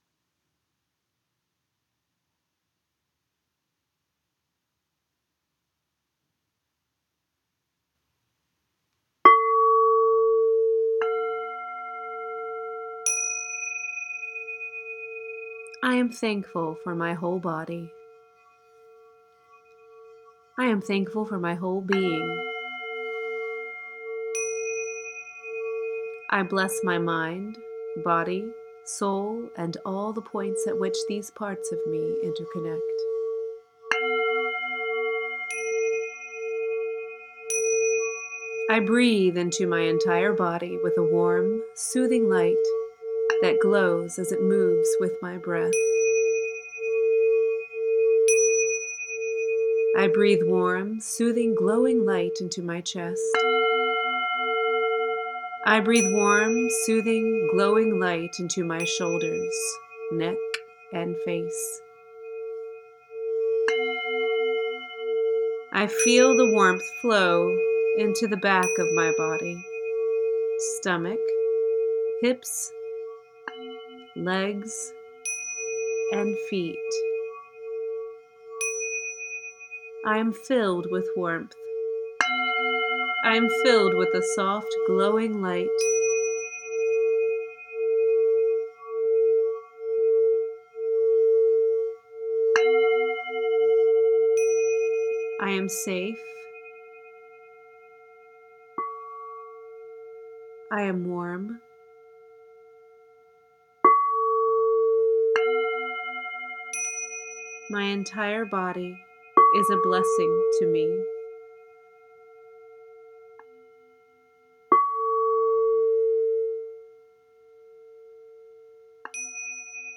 Pause to acknowledge the blessing your body is to you with a short guided visualization.
Guided voice with singing bowls and tingsha bells: